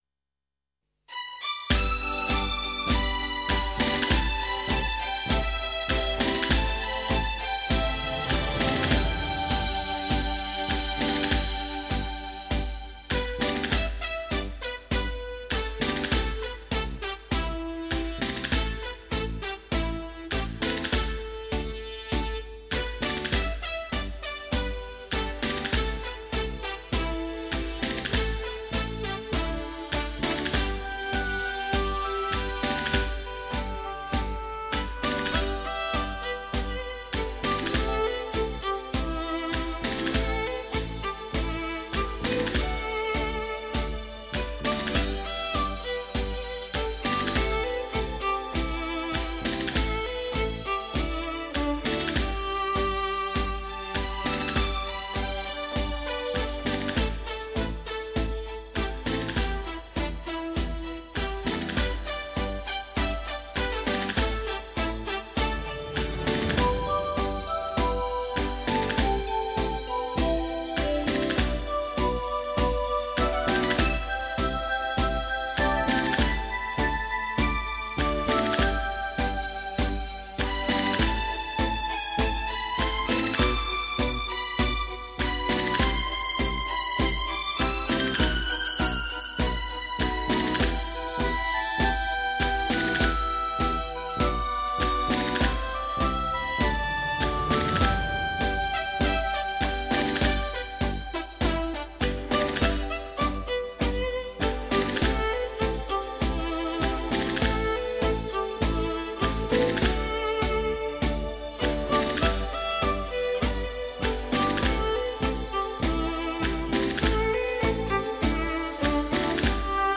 Ballroom Dance For Mid-ages
探戈